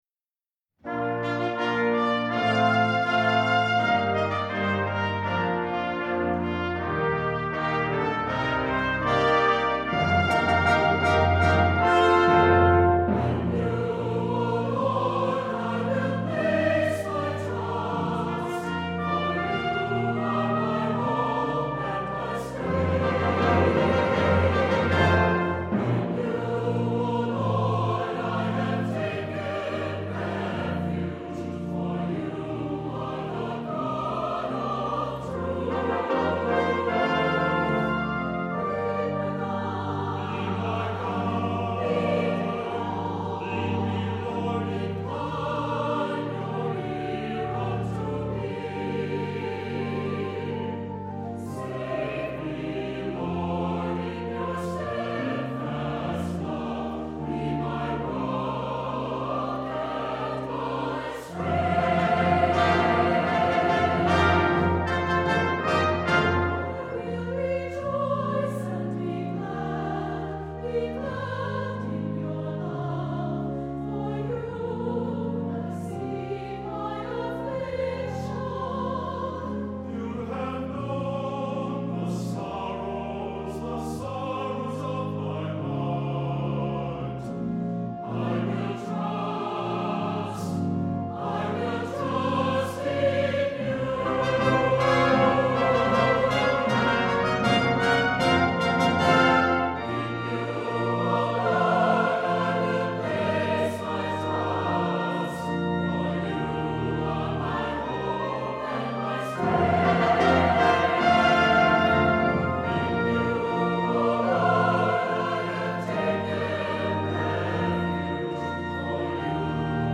Voicing: SATB and Congregation